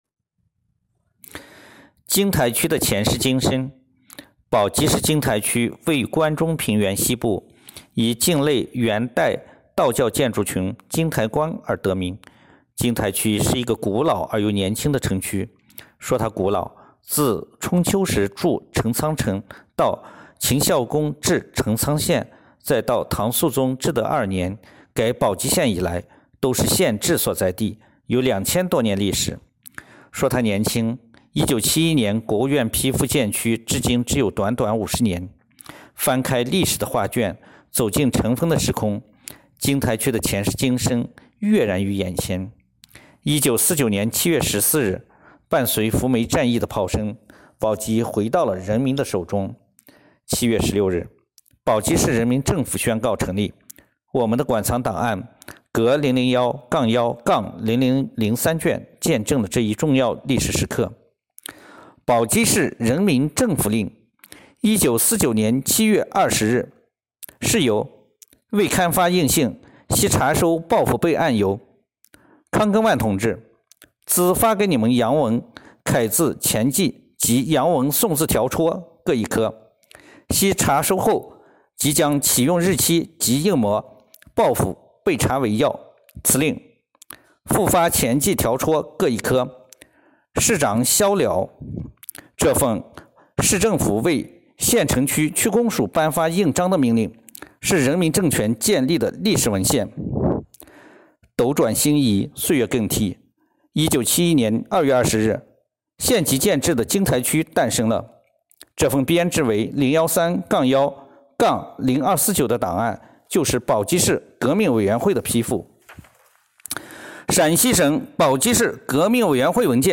【红色档案诵读展播】宝鸡市金台区的前世今生